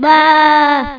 1 channel
BEE.mp3